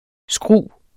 Udtale [ ˈsgʁuˀ ]